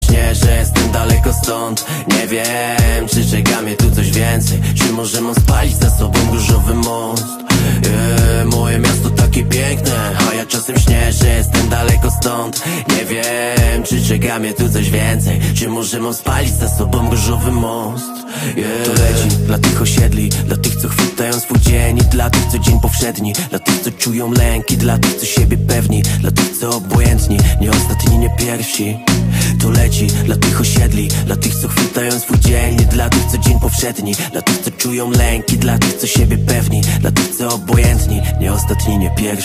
Kategoria Rap/Hip Hop